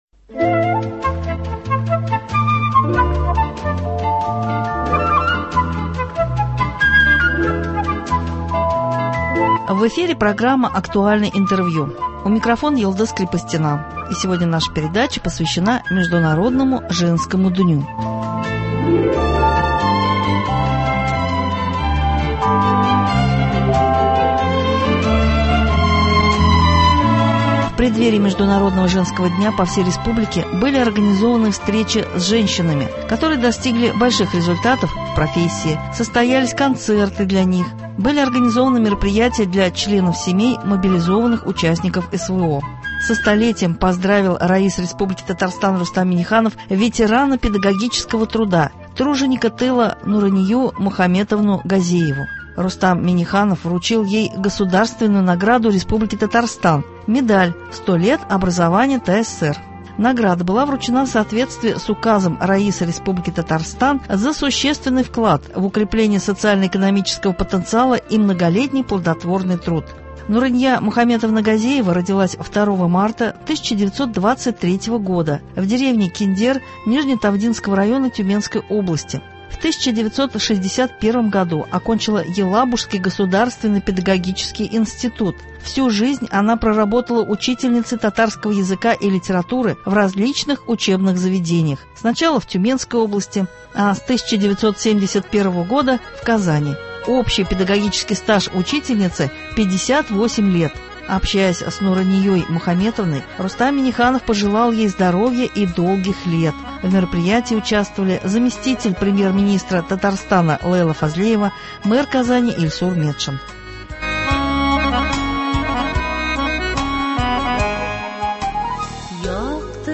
Актуальное интервью (08.03.23)